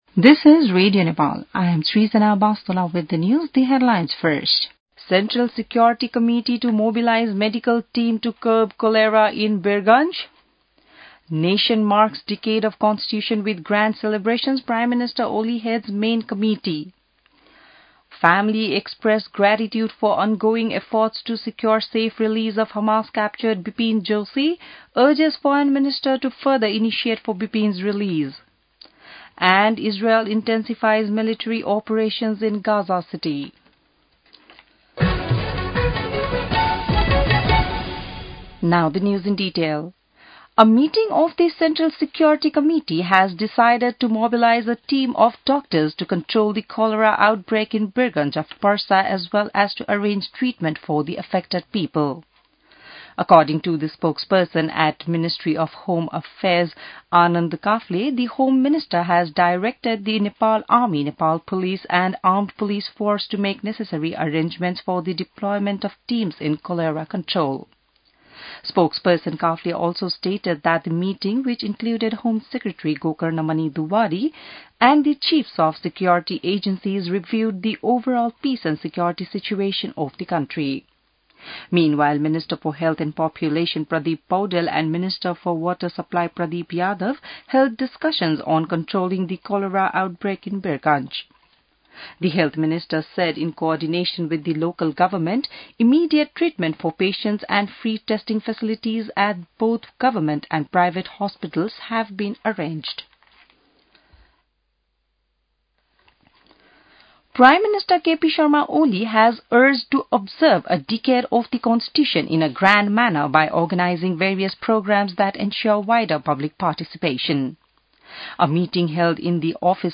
An online outlet of Nepal's national radio broadcaster
बिहान ८ बजेको अङ्ग्रेजी समाचार : ९ भदौ , २०८२